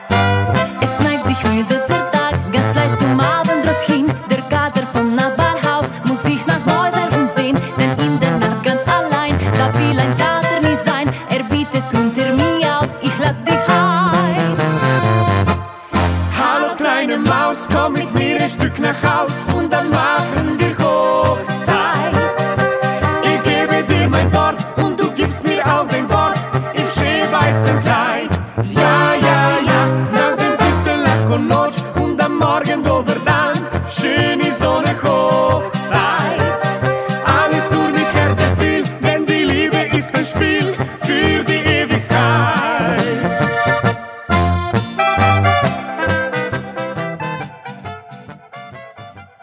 Gattung: Polka
Besetzung: Blasorchester
zu Hause ist, wo sich der typische Oberkrainer-Sound